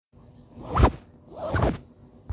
Sound Effects
Whoosh 2
Whoosh 2.m4a